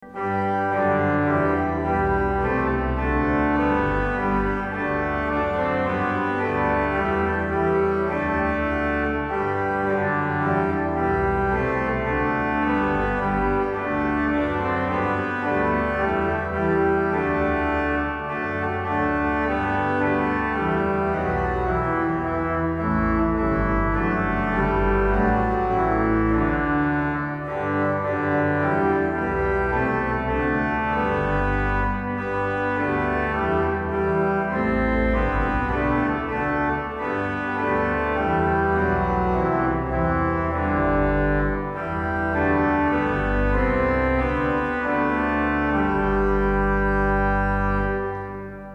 Intonation BH⇒ Schwellwerk: Rohrflöte 8' Oktav 2' Intonation 1. Strophe BH⇒ Hauptwerk: Schwellwerk: Pedal: Koppeln Principal 8' Oktav 4' Rohrflöte 8' Oktav 4' Oktav 2' Subbass 16' Principal 8' Oktav 4' Sw/Hw Sw/Pedal 1.